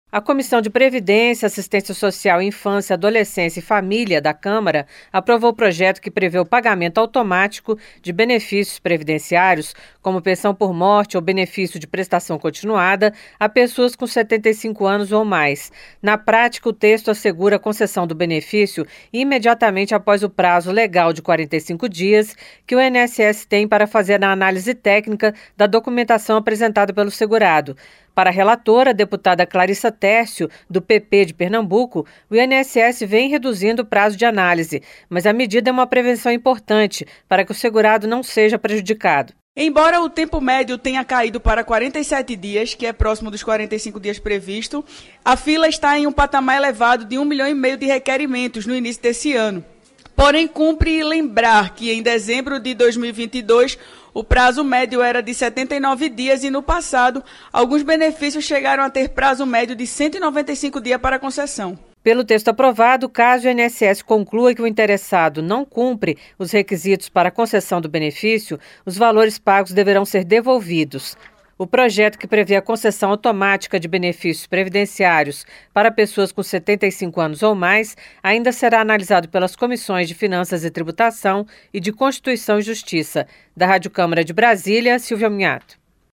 POPULAÇÃO COM MAIS DE 75 ANOS PODE TER MAIS FACILIDADE PARA RECEBER BENEFÍCIOS PREVIDENCIÁRIOS. PROJETO NESSE SENTIDO FOI APROVADO EM COMISSÃO DA CÂMARA, COMO EXPLICA A REPÓRTER